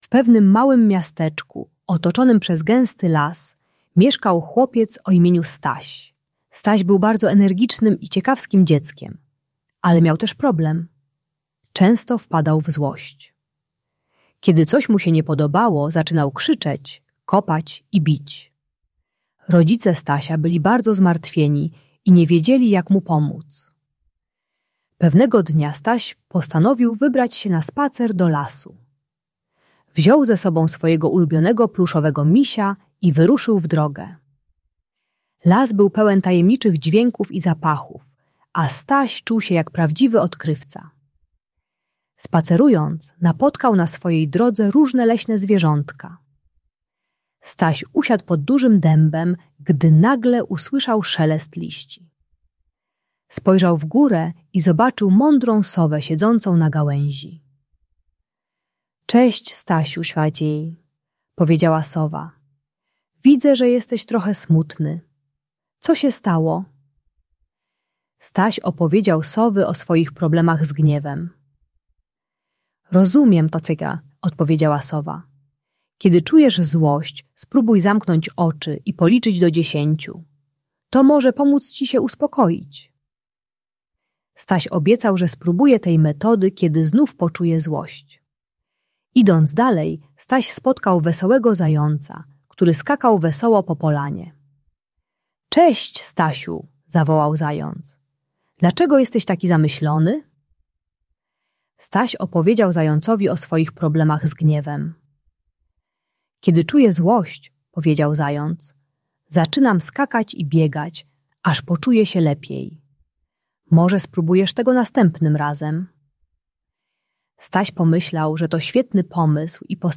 Historia Stasia i jego przyjaciół z lasu - Agresja do rodziców | Audiobajka